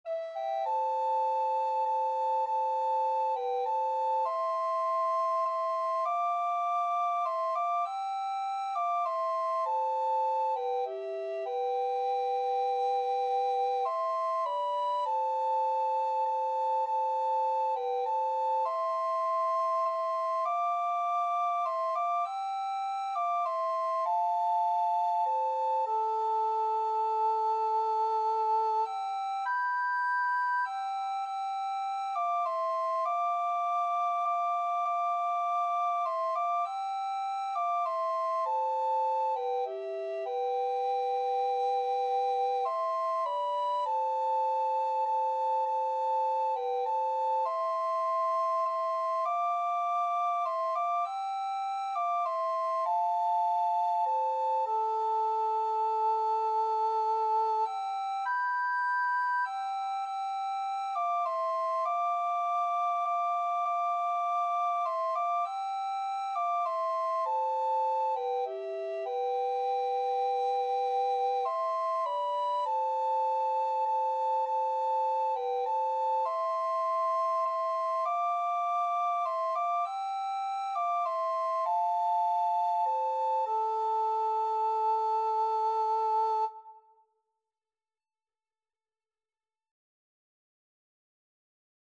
Soprano RecorderAlto Recorder
Traditional Music of unknown author.
C major (Sounding Pitch) (View more C major Music for Recorder Duet )
3/4 (View more 3/4 Music)
Recorder Duet  (View more Easy Recorder Duet Music)
Traditional (View more Traditional Recorder Duet Music)